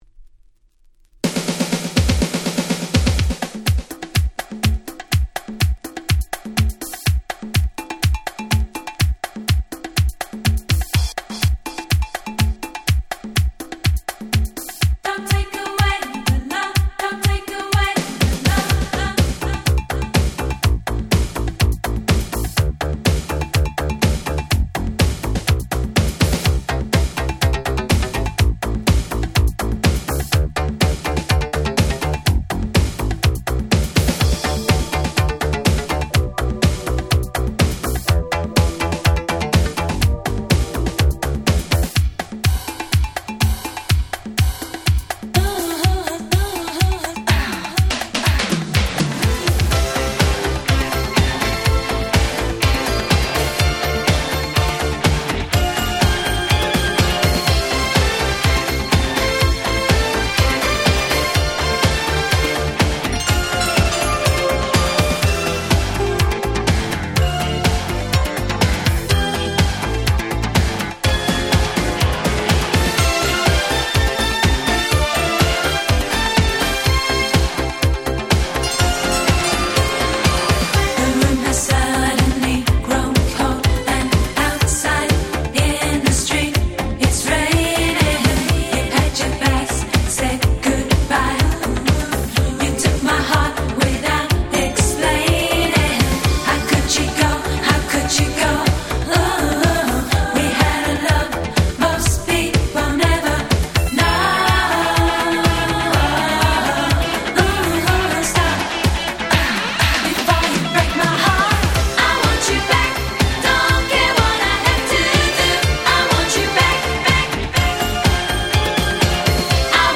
88' Super Hit Euro Beat / Disco !!
抜群に明るい盛り上がる1曲！！